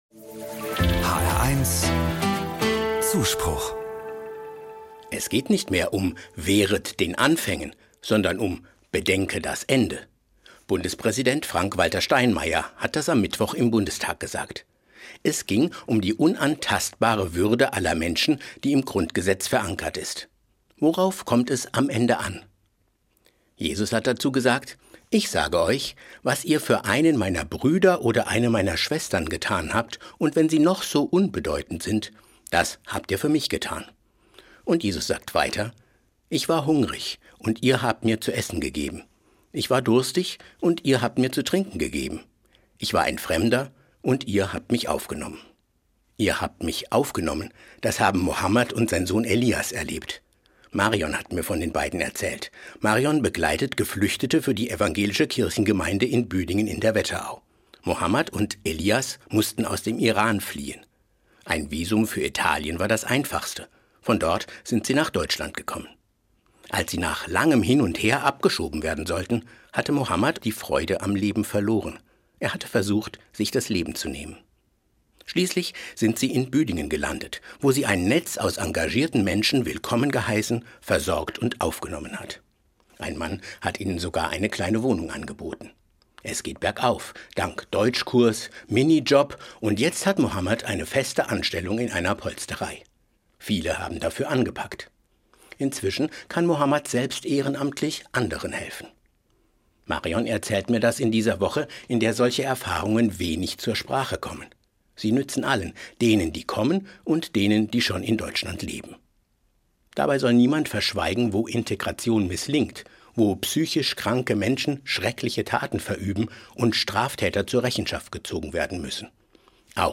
Anmoderation: Das hatte es in der Geschichte der Bundesrepublik noch nie gegeben: Am Mittwoch hat ein Entschließungsantrag zur Verschärfung des Asylrechts im Bundestag mithilfe einer in Teilen rechtsradikalen Partei eine Mehrheit bekommen.